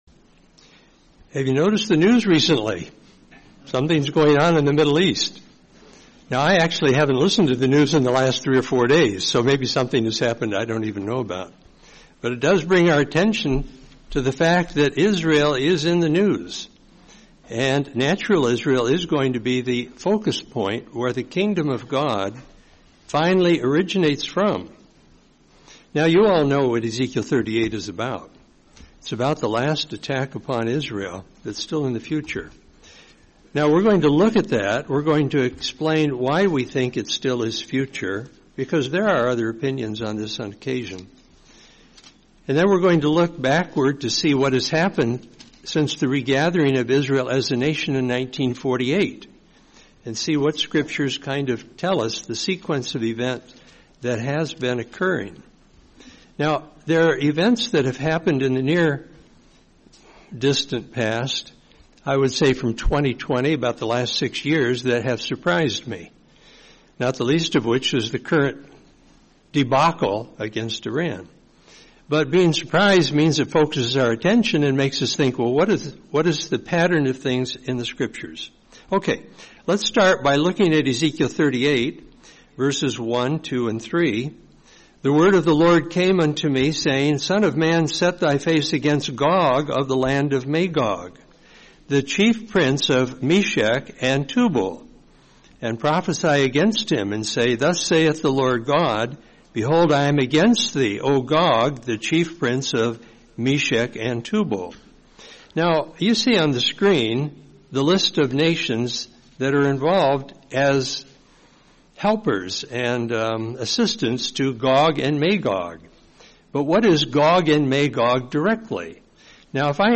Series: 2026 Florida Convention